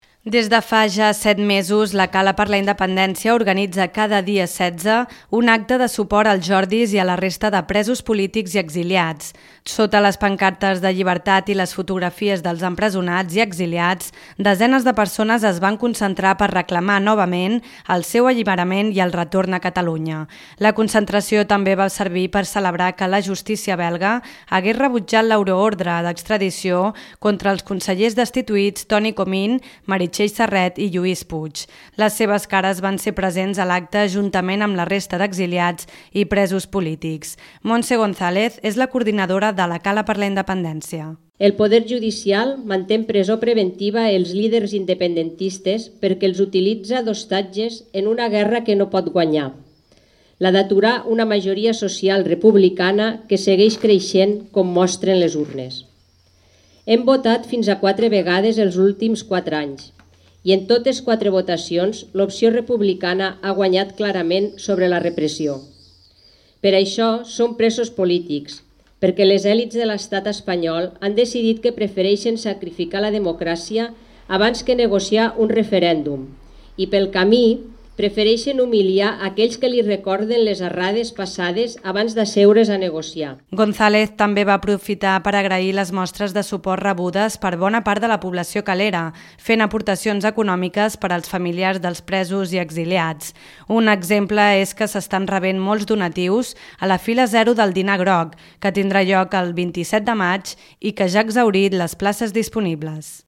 L’ANC fa una concentració a les portes de l’Ajuntament per reclamar la llibertat dels presos polítics i el retorn dels exiliats.